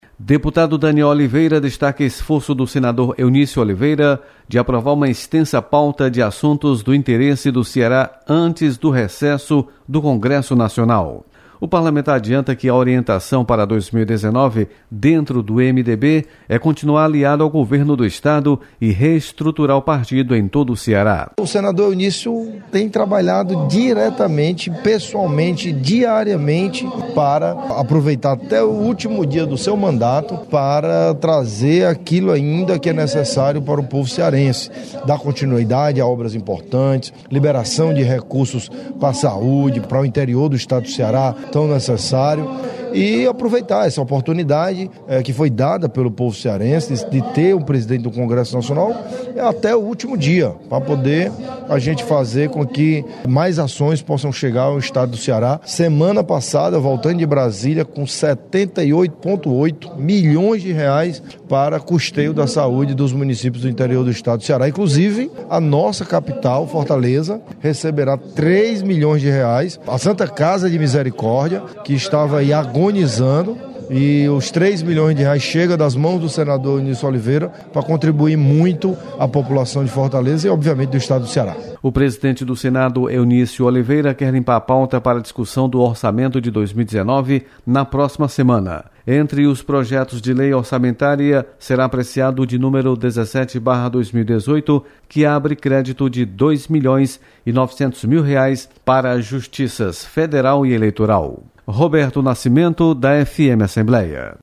Deputado Danniel Oliveira destaca esforço do senador Eunício Oliveira de aprovar projetos de interesse para o Estado. Repórter